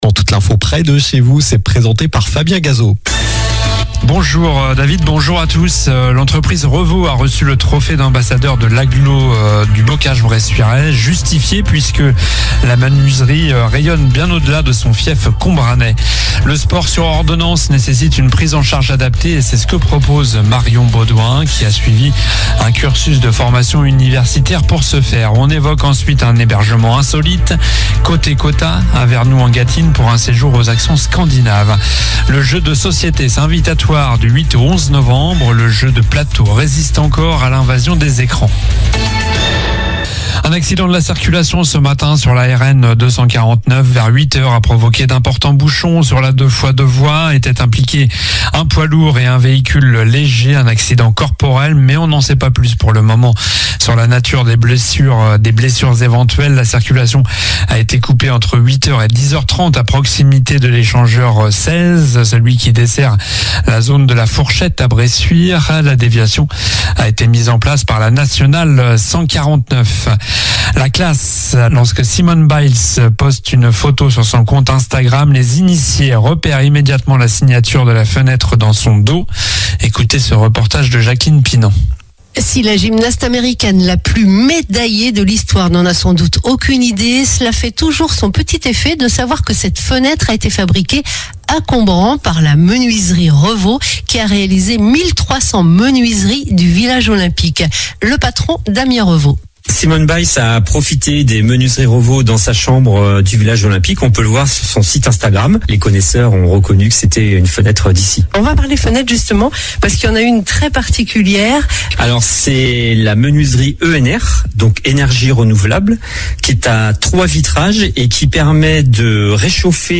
Journal du mercredi 30 octobre (midi)